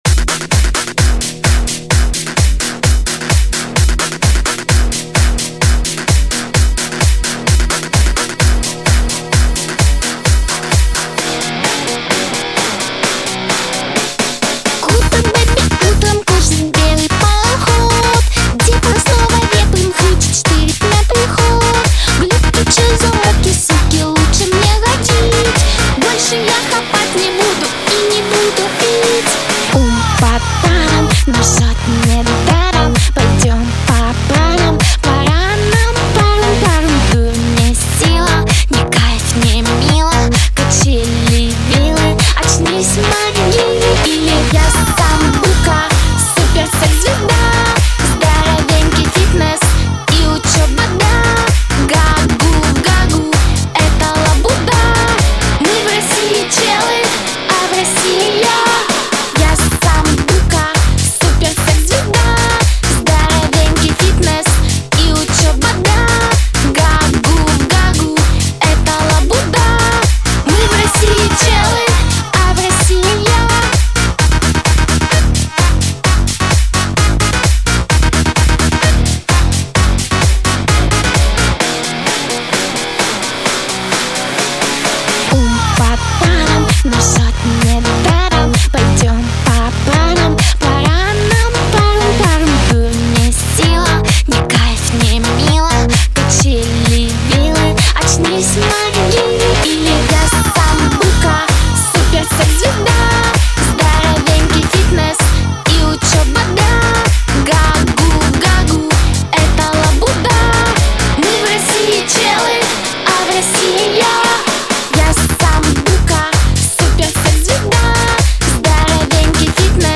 грусть
дуэт , Лирика